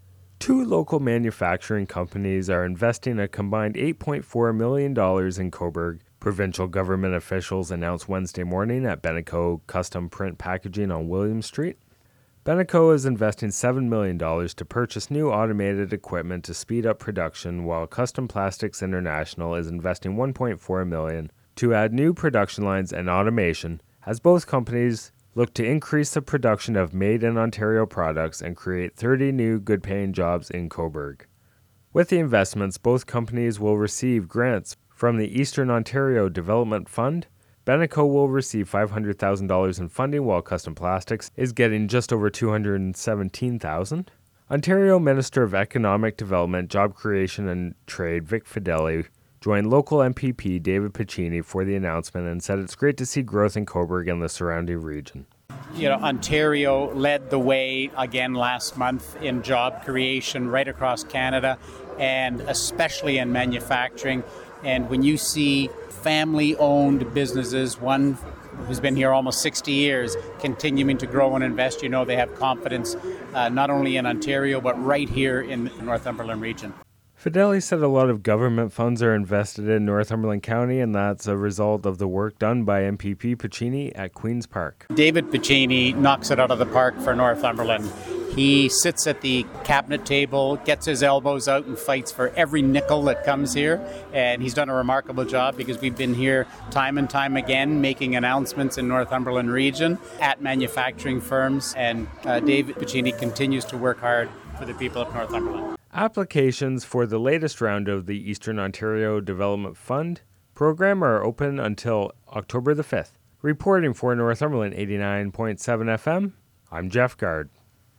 Aug30-business-investment-report.mp3